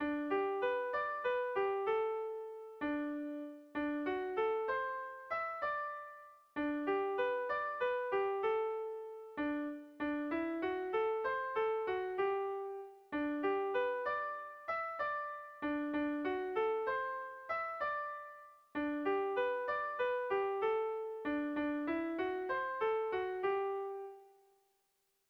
Kontakizunezkoa
Zortziko txikia (hg) / Lau puntuko txikia (ip)
A1A2BA2